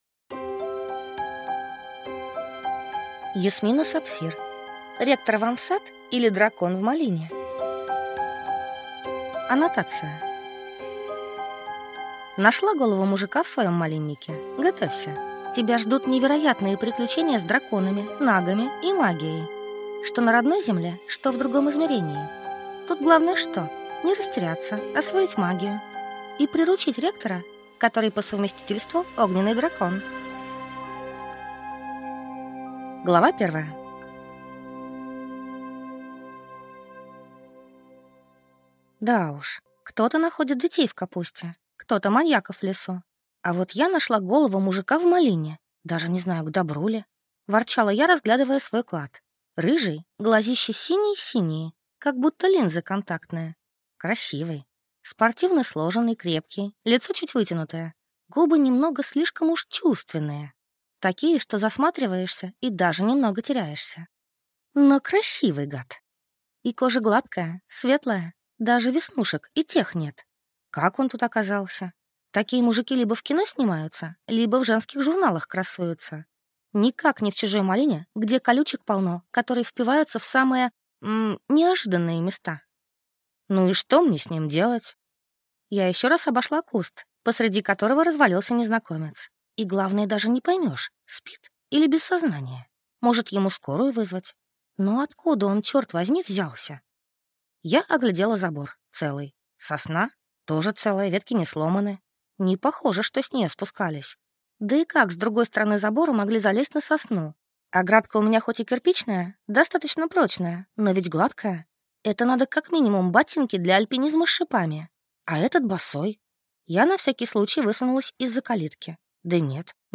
Аудиокнига Ректор вам в сад, или Дракон в малине | Библиотека аудиокниг